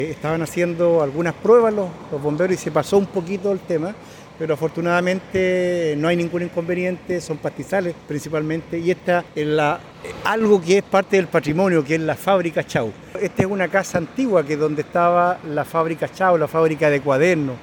cuna-alcalde-chiguayante.mp3